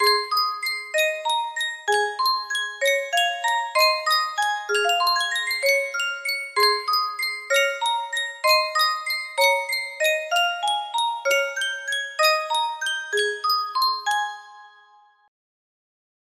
Full range 60